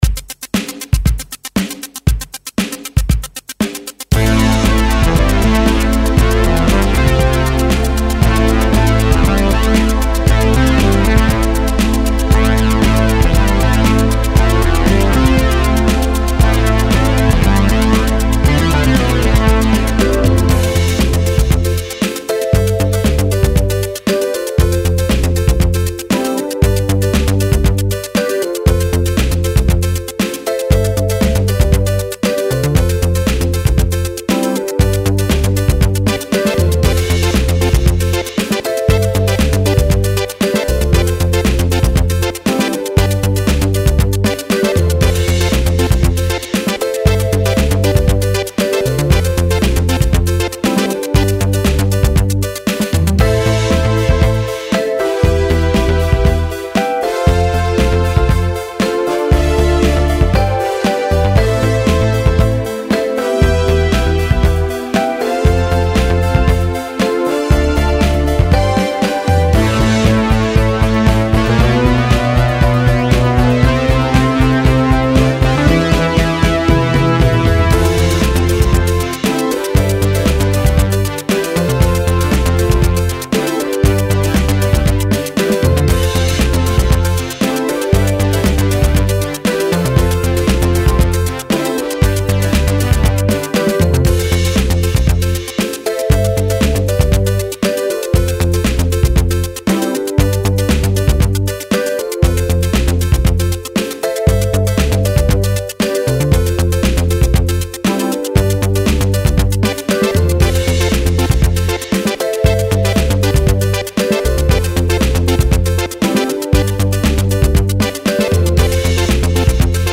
All sounds apart from the drums are Kronos.